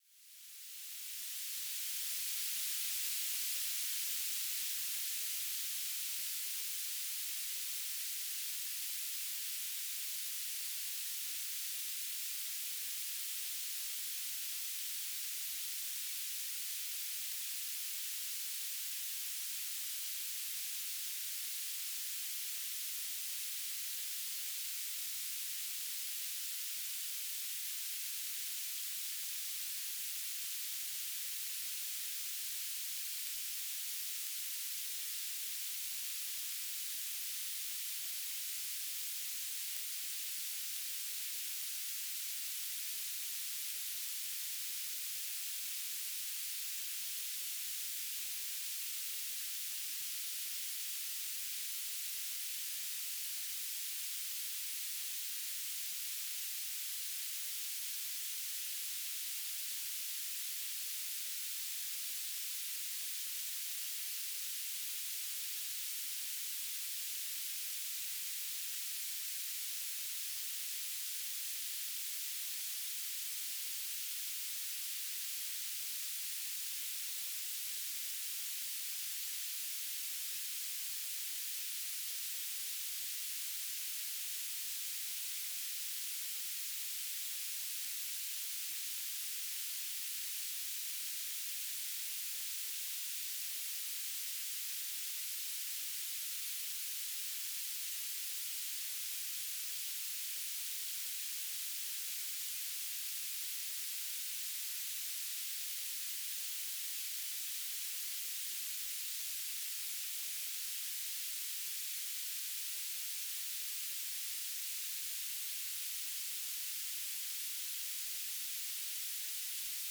"transmitter_description": "Mode U - BPSK1k2 - Beacon",
"transmitter_mode": "BPSK",